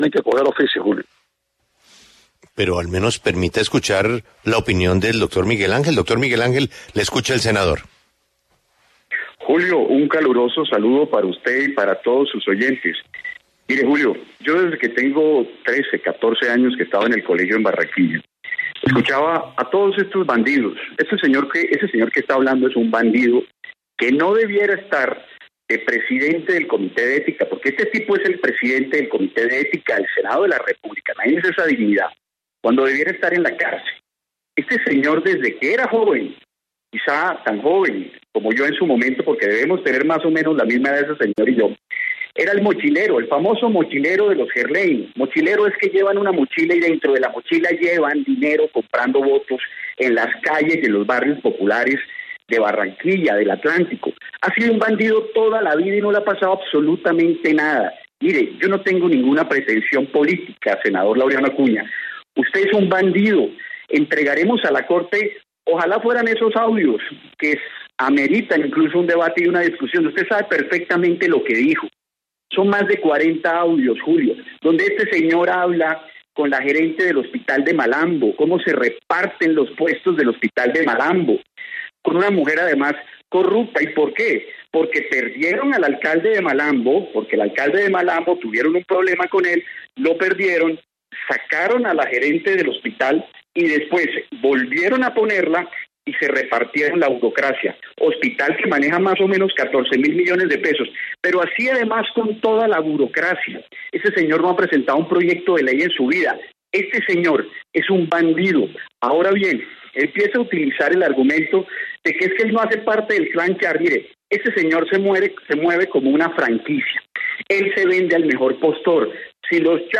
En diálogo con La W, Miguel Ángel del Río, abogado de la excongresista Aida Merlano, le respondió al senador Laureano Acuña por el audio revelado sobre la presunta compra de 70.000 votos en el Atlántico en estas elecciones.